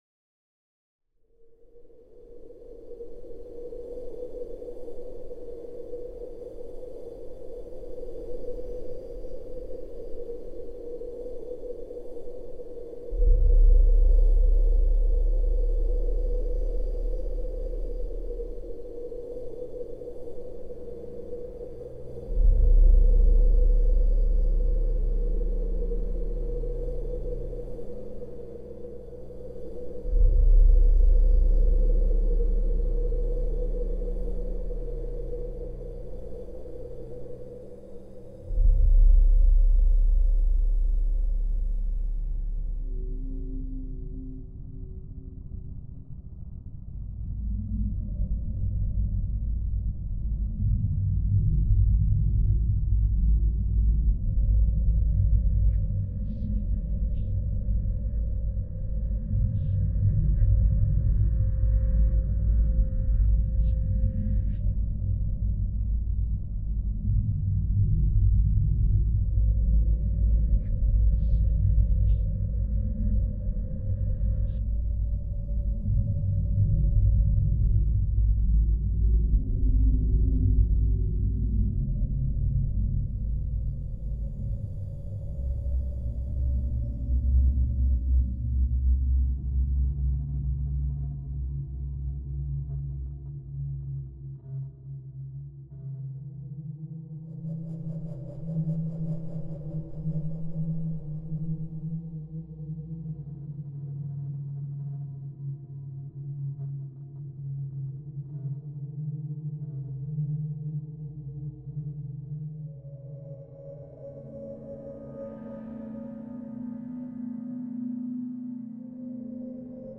Royal Court production, starring Lisa Dwan, of Samuel Beckett's Not I, Footfalls and Rockaby
A clip of Lisa’s extraordinary performance in Not I